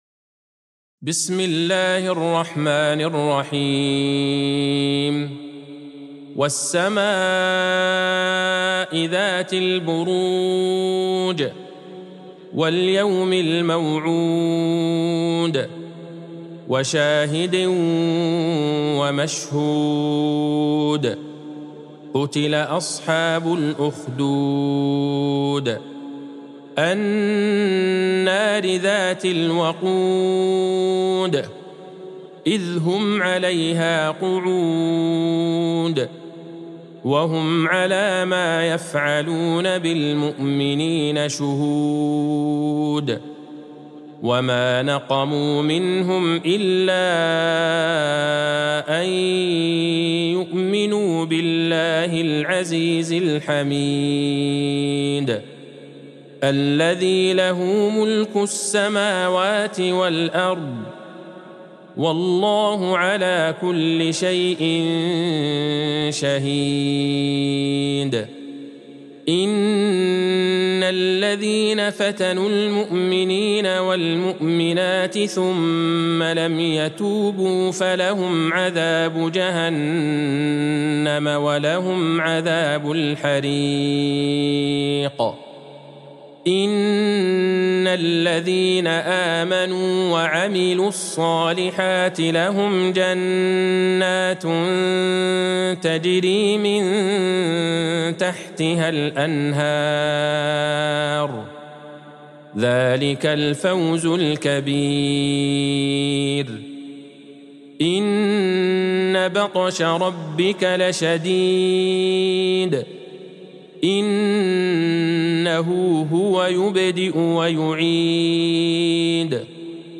سورة البروج Surat Al-Buruj | مصحف المقارئ القرآنية > الختمة المرتلة ( مصحف المقارئ القرآنية) للشيخ عبدالله البعيجان > المصحف - تلاوات الحرمين